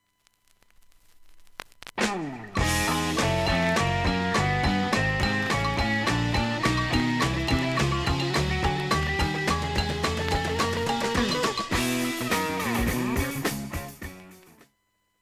音質良好全曲試聴済み。 瑕疵部分 A-1出だし4-5回チリプツ出ます。
音質目安にどうぞ